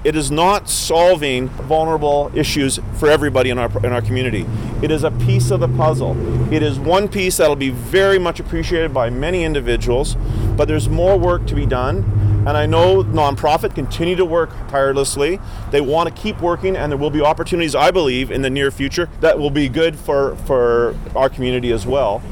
Comox Valley MLA Don McRae says this is huge for the city of Courtenay, and the whole Comox Valley. However, he notes there is still more work to do to solve the issue of homelessness in the community.